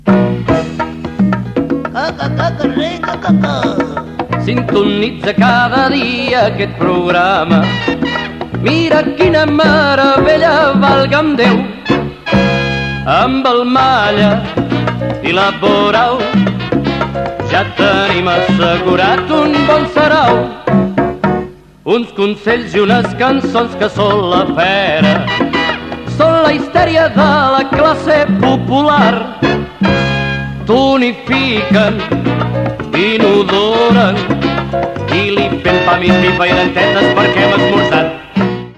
Indicatiu cantat del programa.